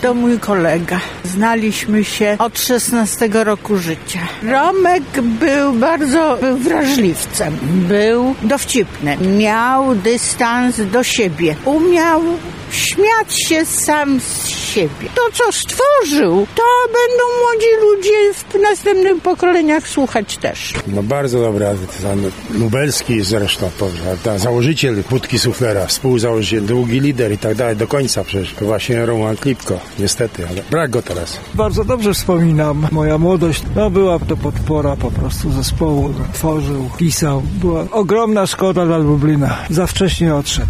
O to jak wspominają artystę zapytaliśmy mieszkańców Lublina.
sonda